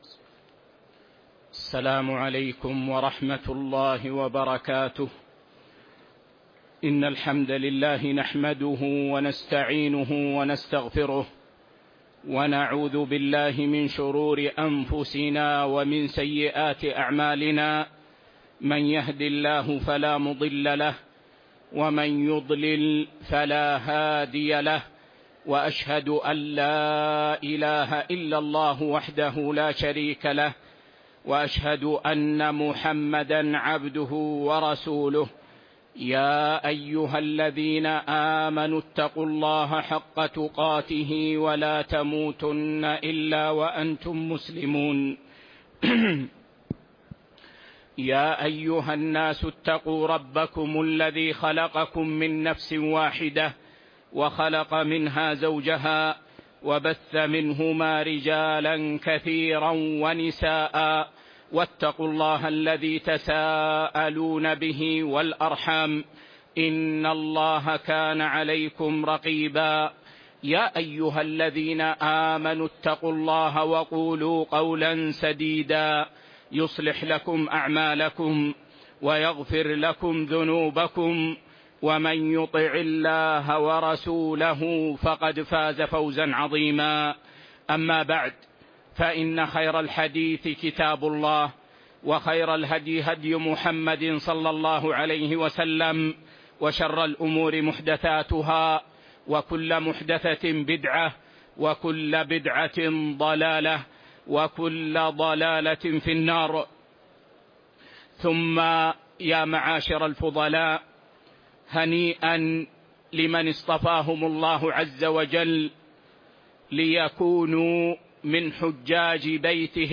صحيح مسلم شرح